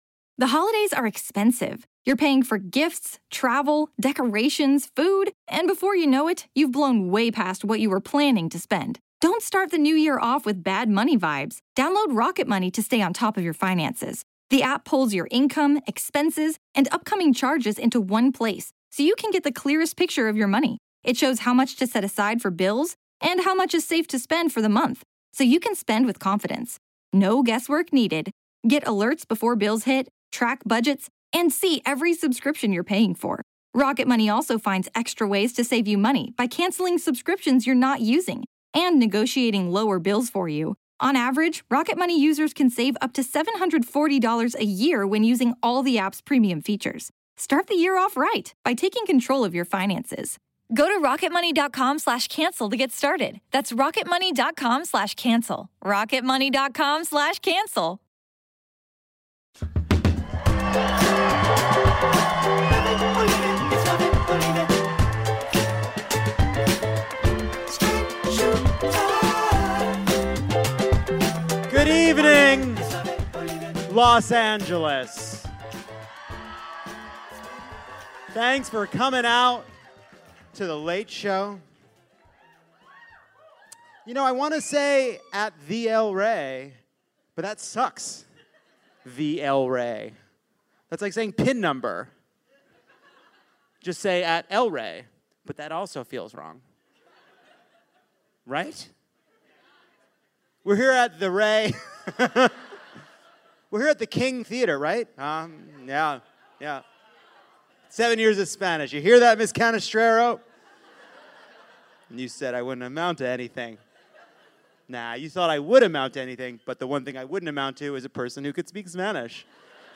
Disturbing allegations emerge against Brett Kavanaugh, CBS ousts Les Moonves and confronts a misogynist workplace culture, Serena Williams deals with a bunch of bullshit, Tucker Carlson goes full fascist, and in honor of the high holidays, we atone for how our culture mistreated Monica Lewinsky, Marcia Clark, and Britney Spears. Senator Brian Schatz of Hawaii joins Jon to talk about the Supreme Court and being the best senator on Twitter, and Erin Ryan and comedian Janelle James join to break down a big week of news.